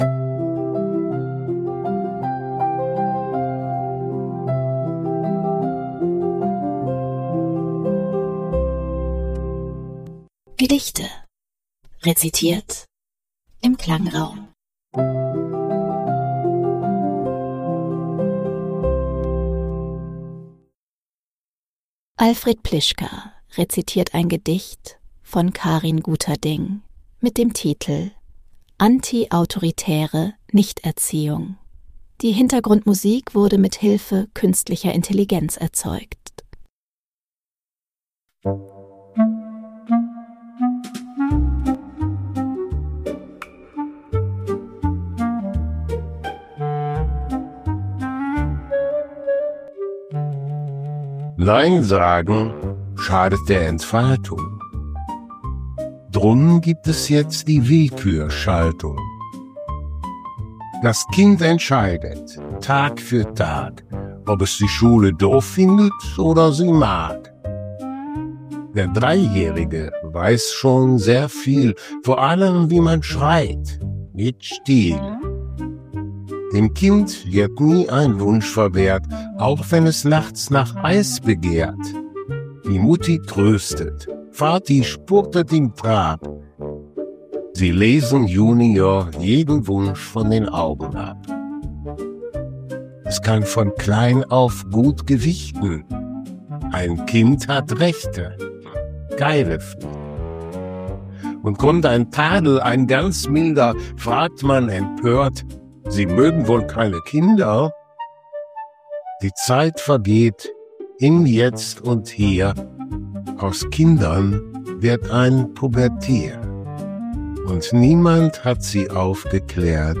wurde mithilfe Künstlicher Intelligenz erzeugt.